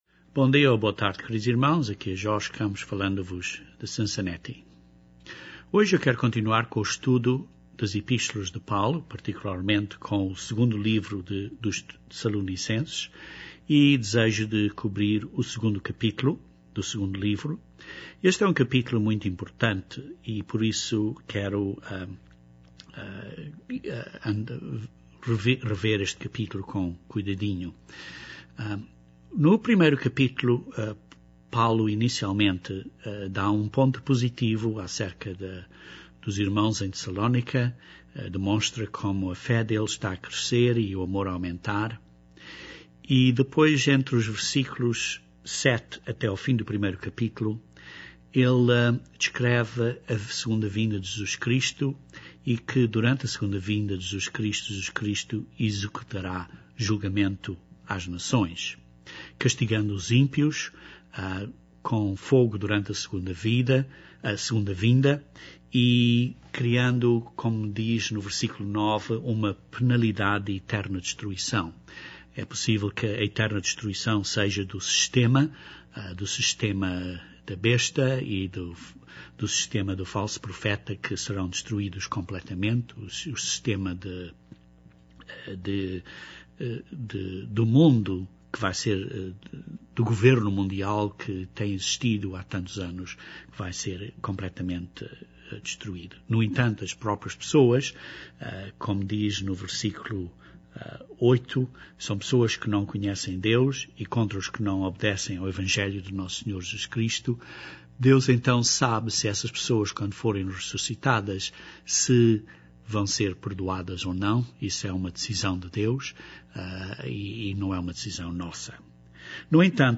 Neste segundo capítulo Paulo avisa os irmãos de Tessalónica para não serem enganados, pois Jesus Cristo só virá depois da apostasia da verdade e que o homem da iniqüidade seja revelado. Neste estudo bíblico analisamos esta profecia do segundo capítulo de 2 Tessalonicenses.